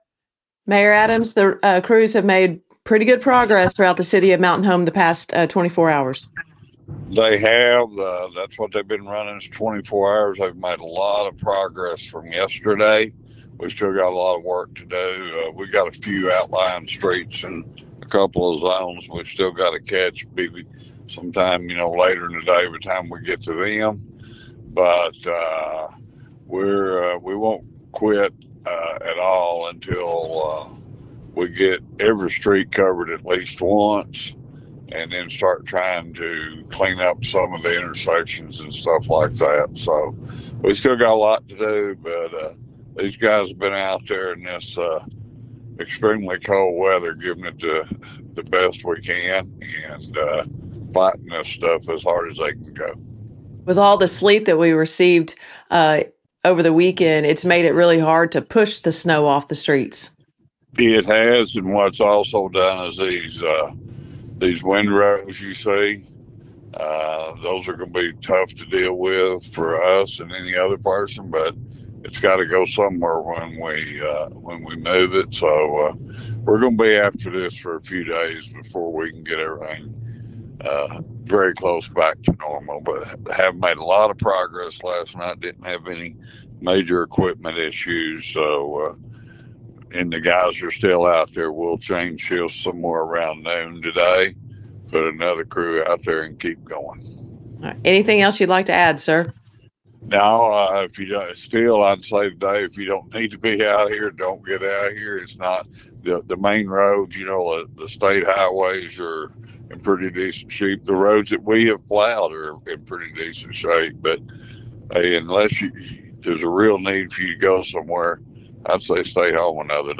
Mayor Hillrey Adams spoke with KTLO News about the road conditions in the city of Mountain Home, and says they’re making progress.